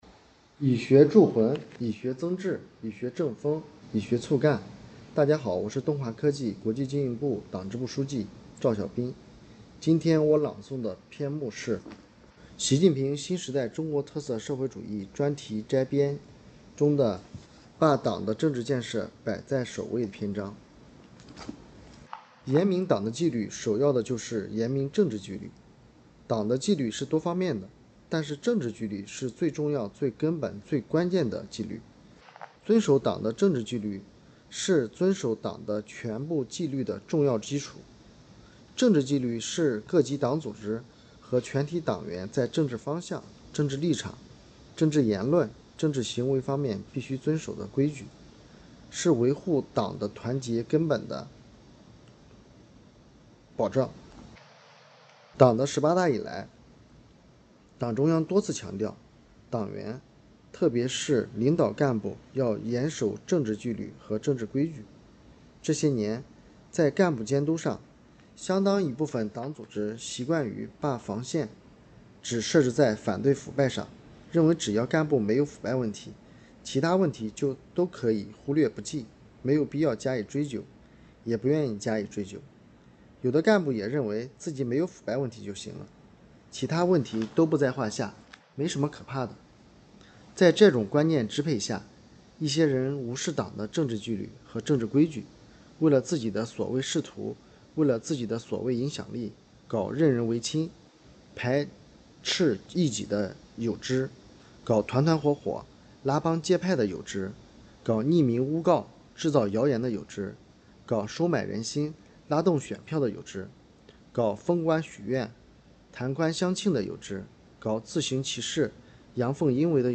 诵读人